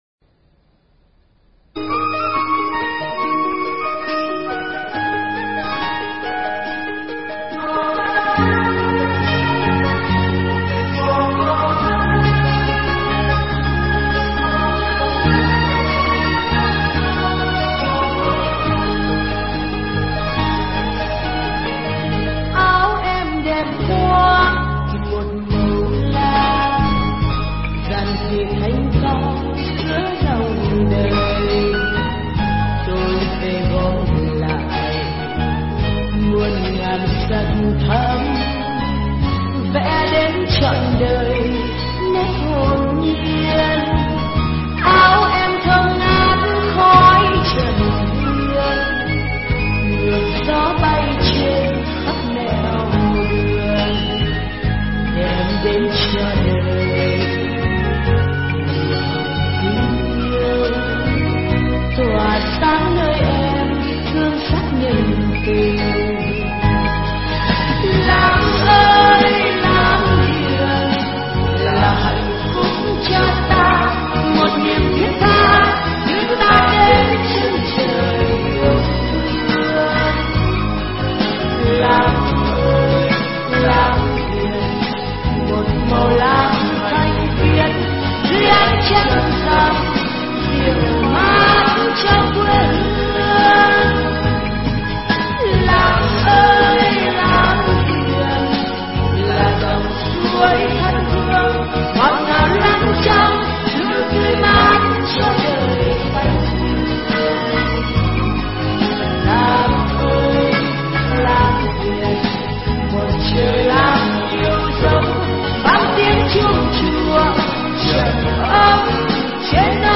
Mp3 Đạo Từ – Thuyết pháp
tại chùa Phước Duyên (tp.Huế)